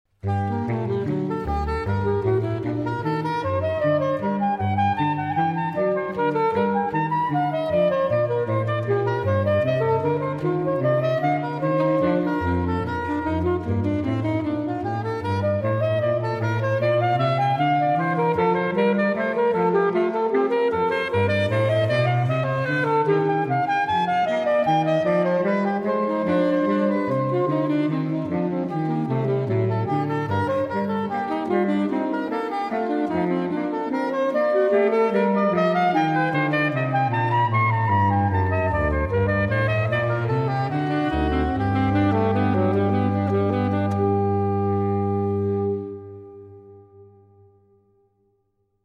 samples of the music from the concert